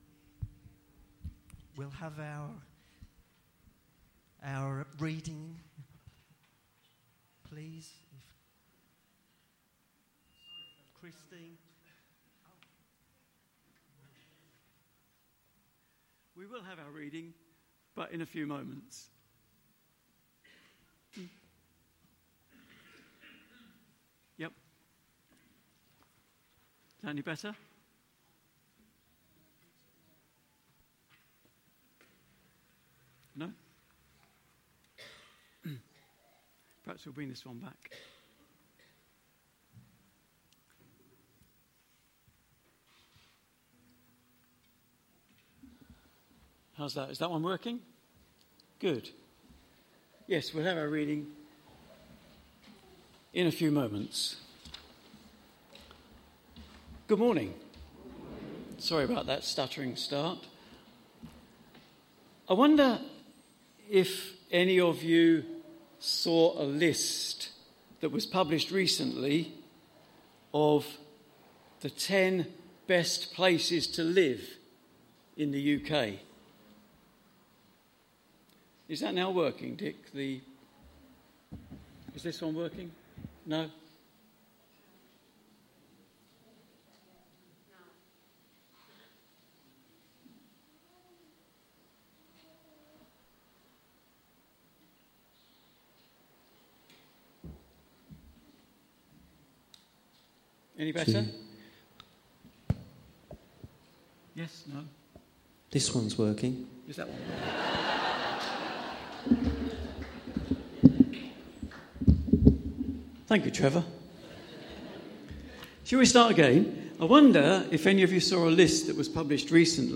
A message from the series "The Book of Daniel."